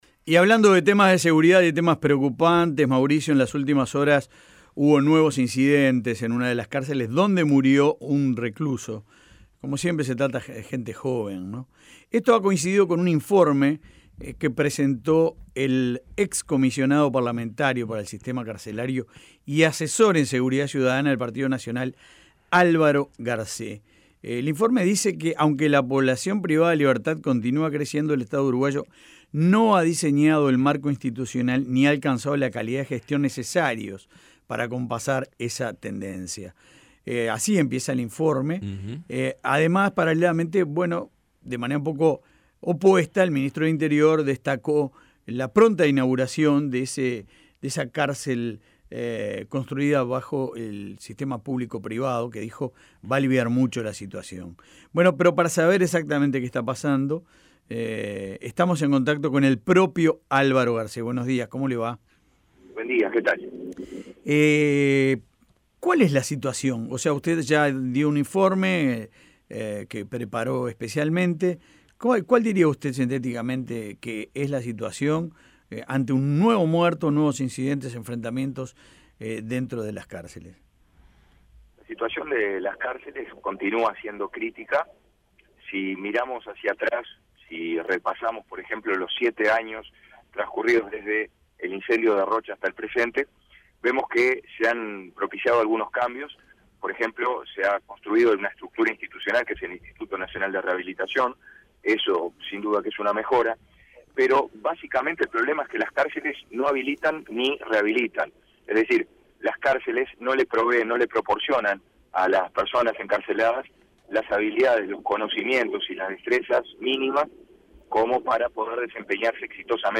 El asesor en seguridad ciudadana del Partido Nacional y ex comisionado parlamentario para el sistema carcelario, Álvaro Garcé, habló sobre su informe de las cárceles en La Mañana de El Espectador.